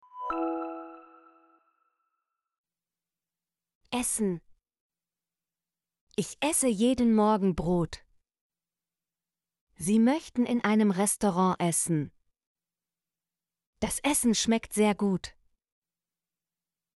essen - Example Sentences & Pronunciation, German Frequency List